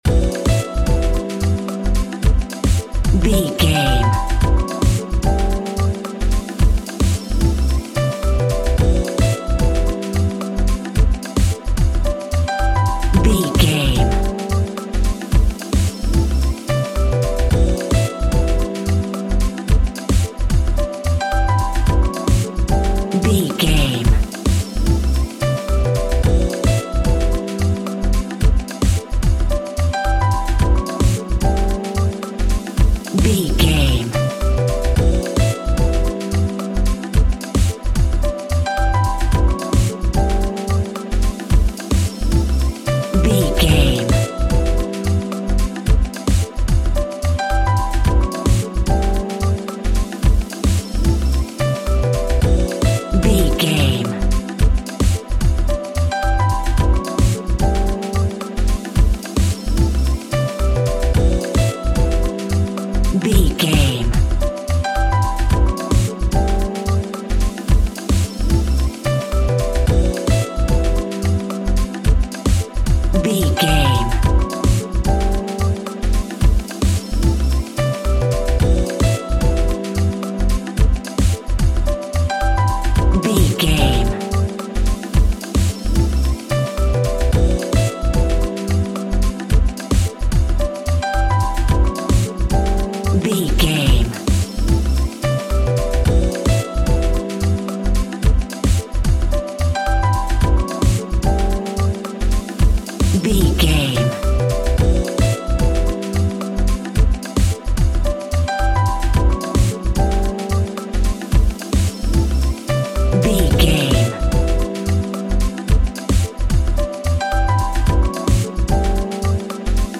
Also with small elements of Dub and Rasta music.
Uplifting